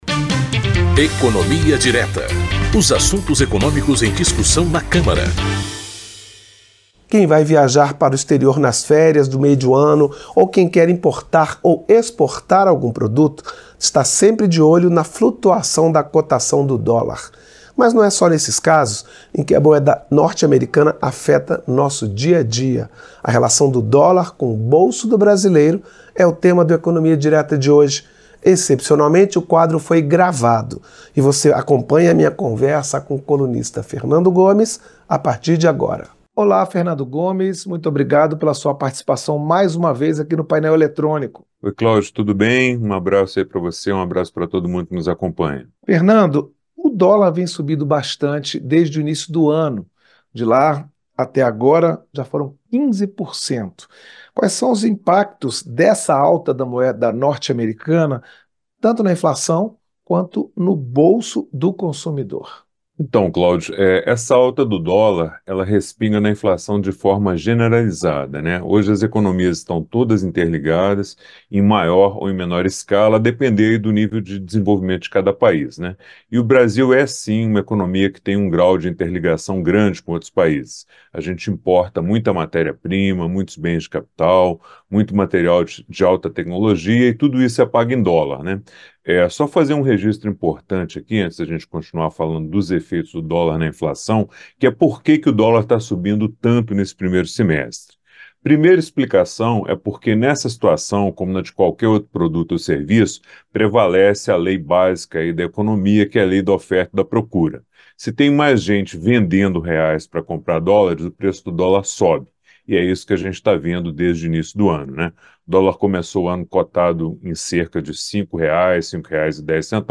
Programas da Rádio Câmara
Apresentação